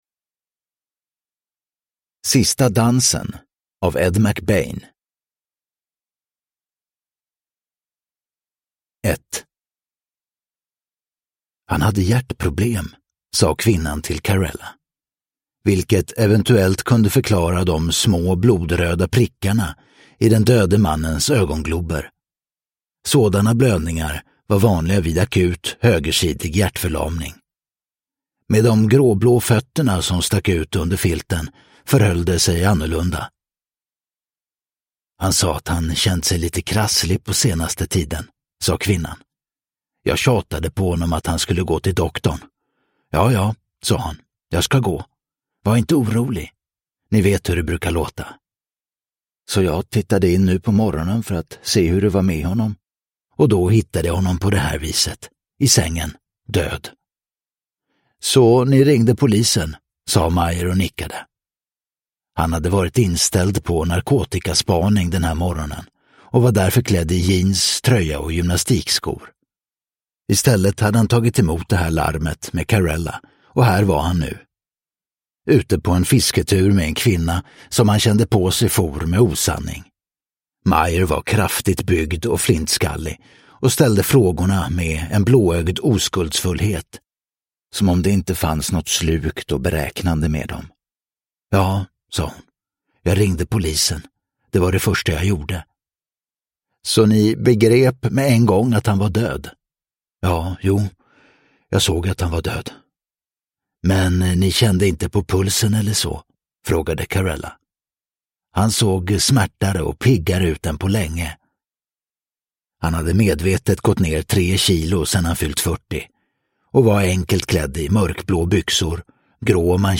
Sista dansen – Ljudbok – Laddas ner